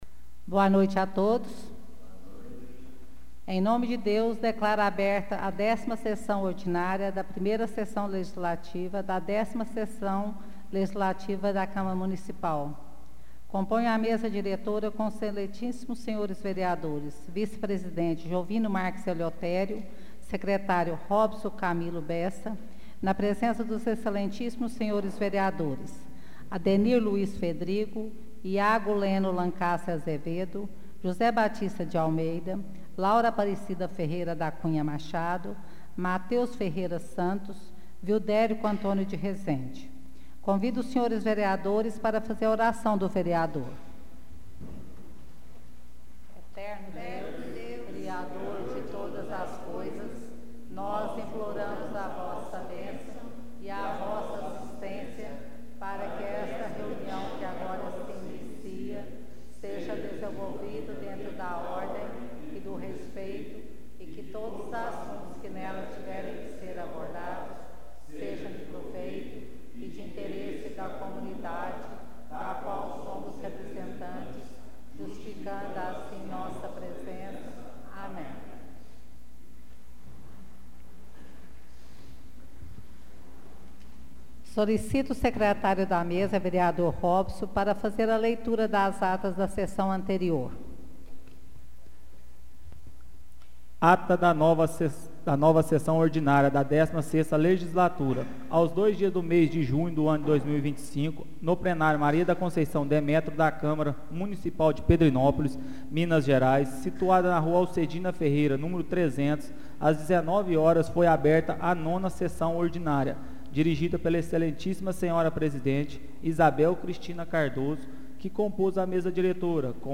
Áudio da 10ª Sessão Ordinária de 2025 — Câmara Municipal de Pedrinópolis